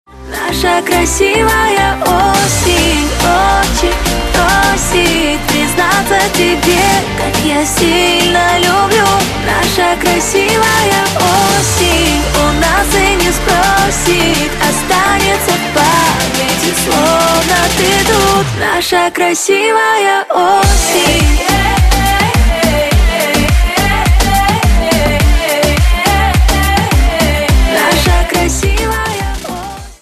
поп
женский вокал
dance
красивый женский голос